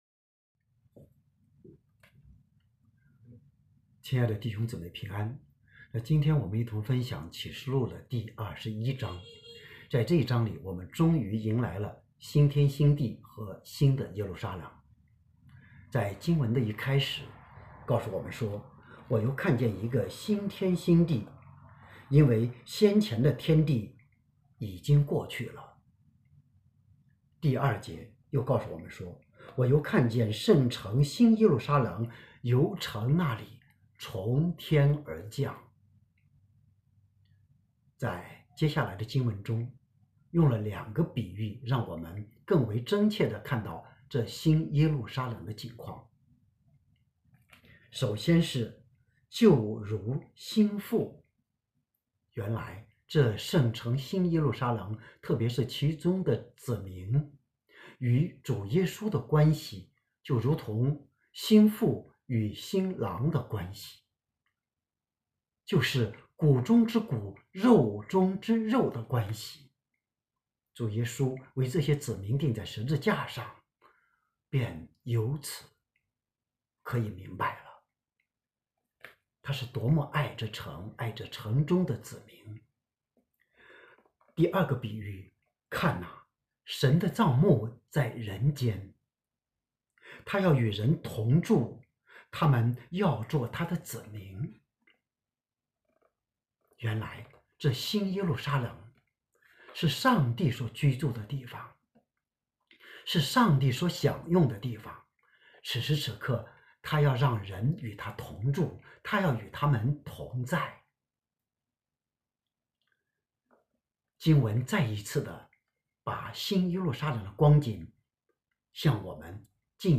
新天新地新耶路撒冷》 证道